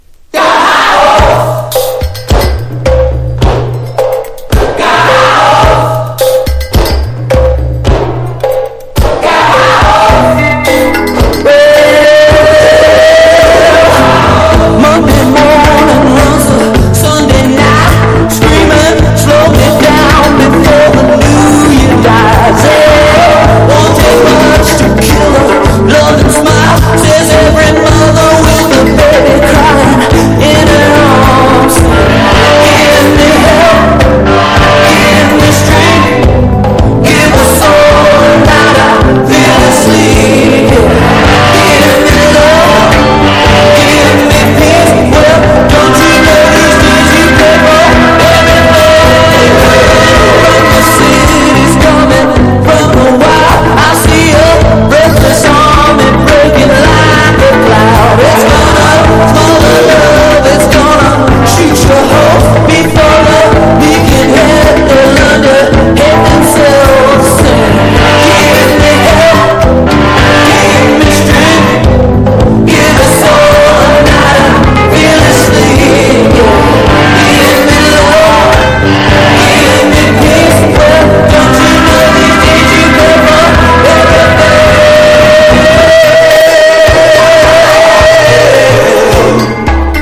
アコースティックなメロディとロカビリーのワイルドさが詰まったクールなR&Rアルバム！